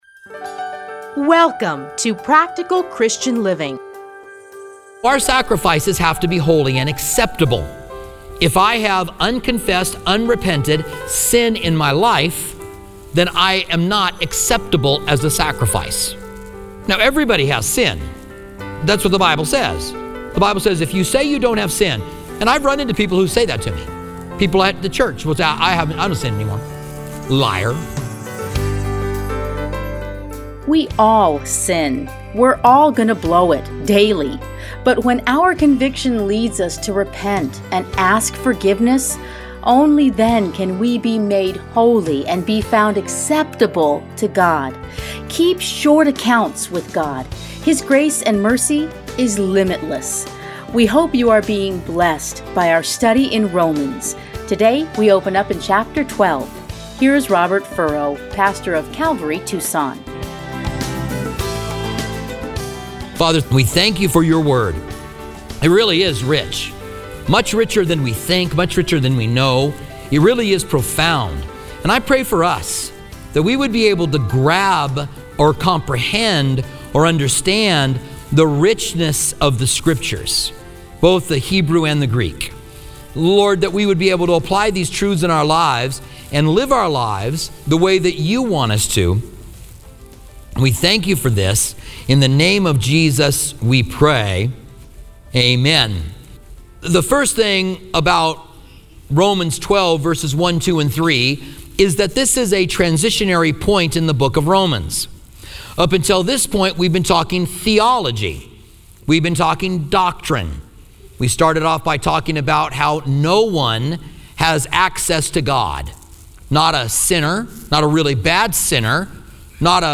Practical Christian Living. Listen here to his commentary on Romans.